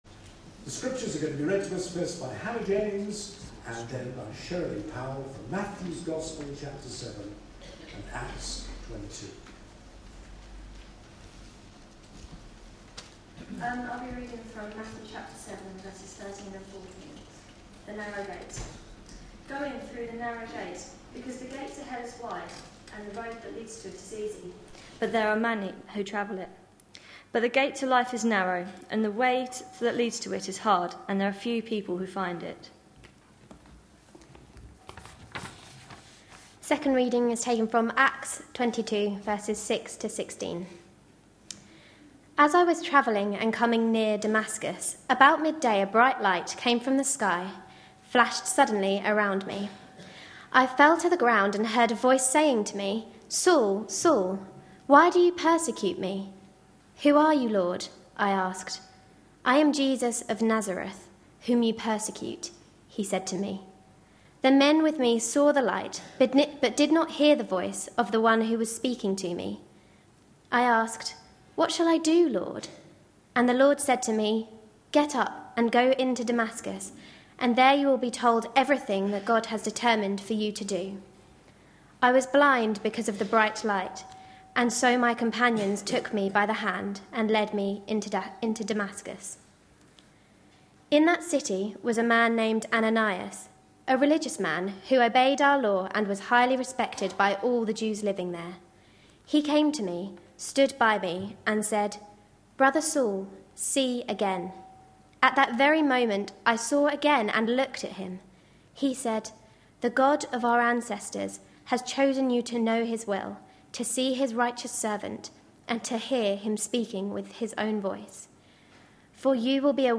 A sermon preached on 12th May, 2013.
This service included two baptisms.